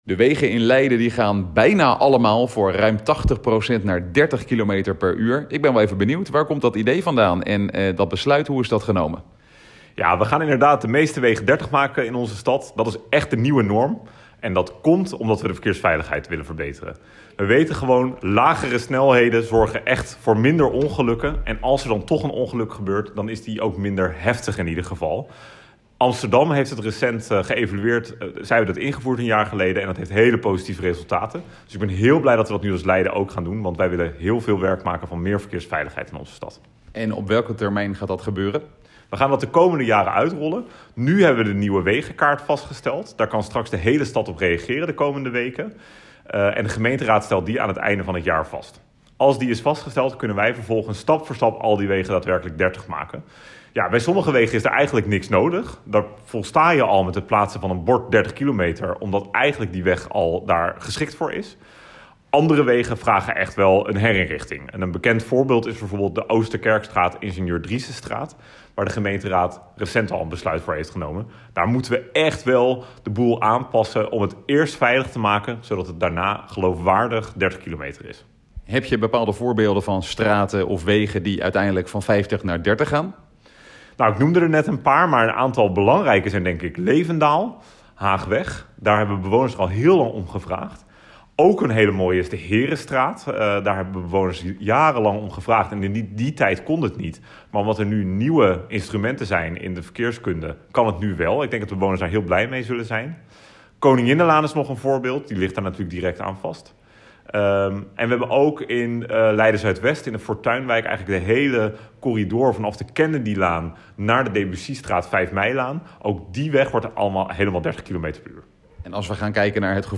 Met deze maatregel wil de gemeente de verkeersveiligheid verbeteren. Volgens wethouder Ashley North wordt 30 kilometer per uur de nieuwe norm.
AUDIO: Ashley North over de uitbreiding van de 30-kilometer wegen